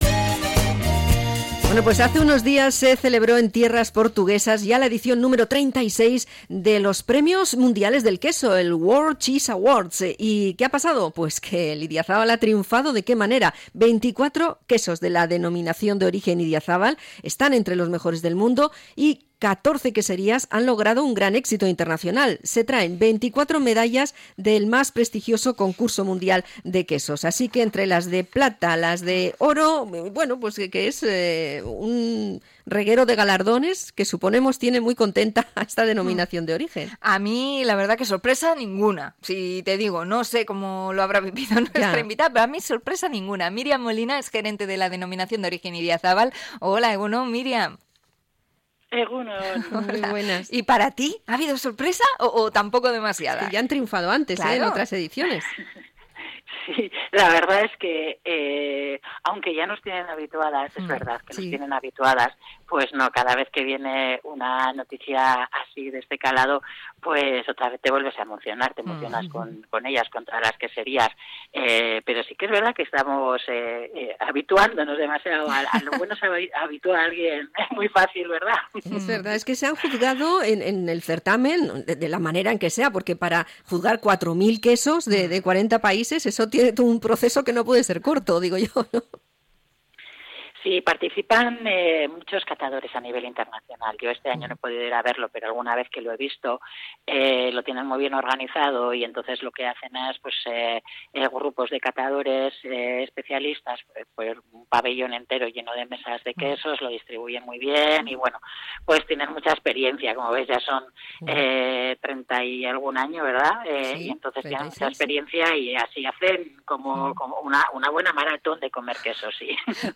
Entrevista a la DOP Idiazabal por los premios World Cheese Awards